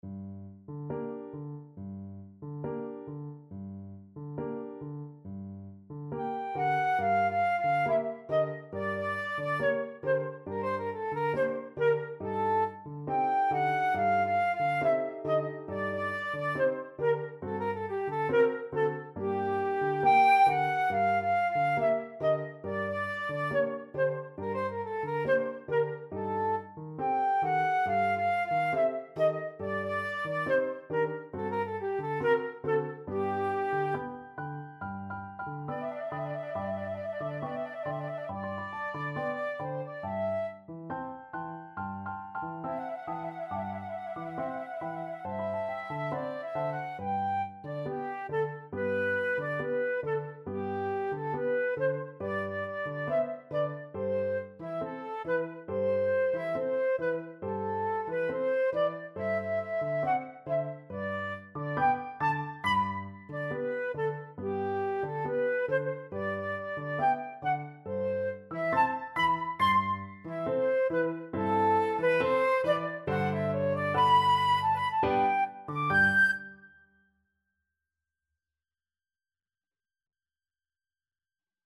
2/4 (View more 2/4 Music)
G minor (Sounding Pitch) (View more G minor Music for Flute Duet )
Allegretto quasi Andantino =69 (View more music marked Andantino)
Flute Duet  (View more Intermediate Flute Duet Music)
Classical (View more Classical Flute Duet Music)